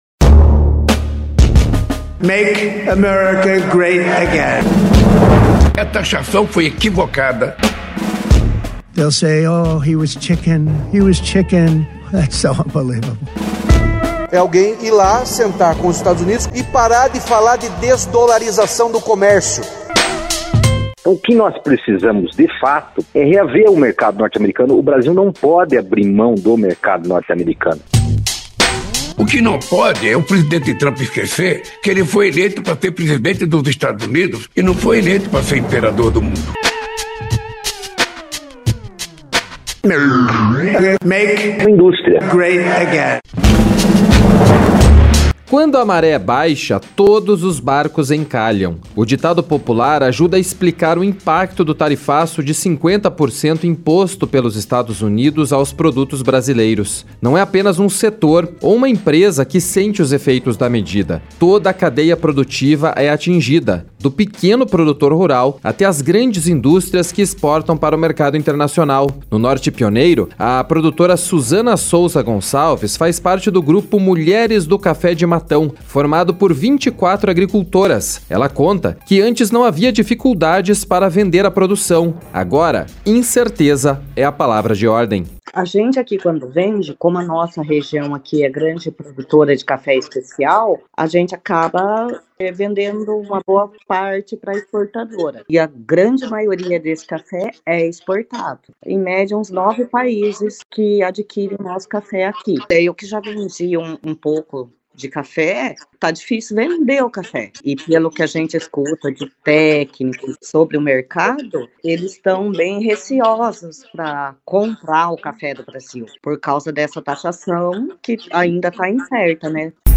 Reportagem 3 – Toda a cadeia produtiva é afetada